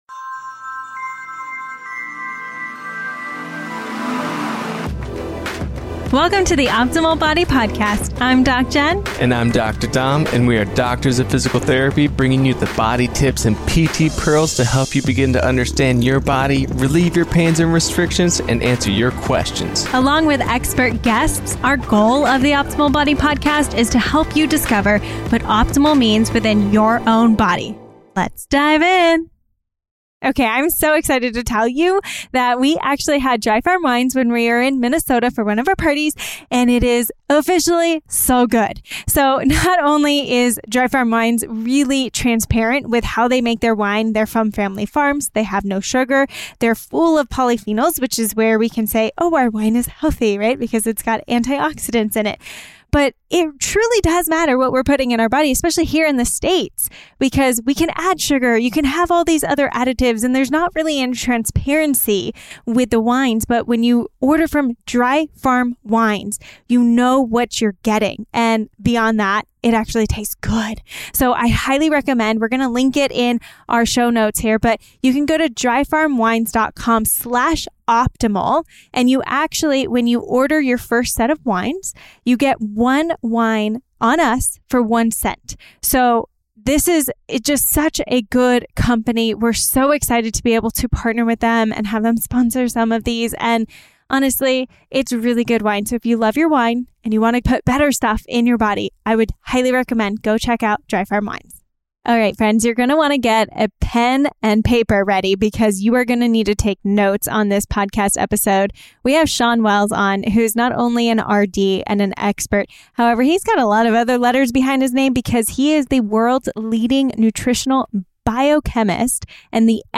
Nutrition and Supplement Masterclass with Expert Fomulator